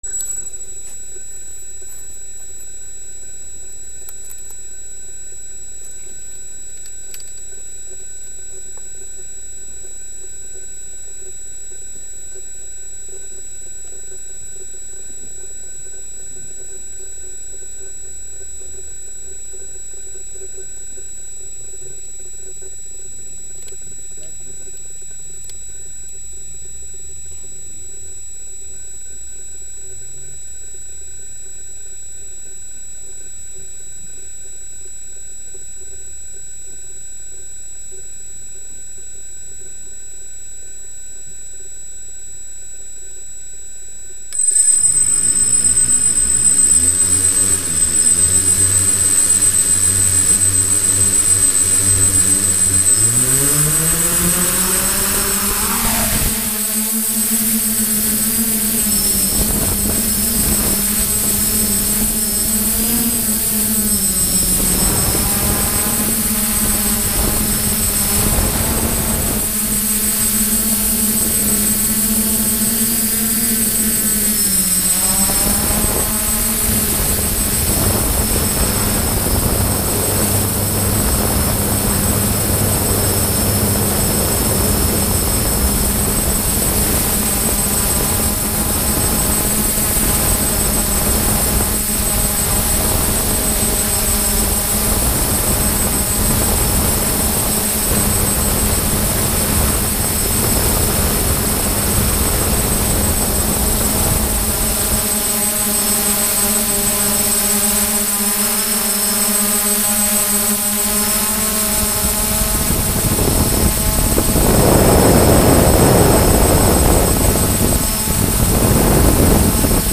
Windrad ohne Prop_434.ogg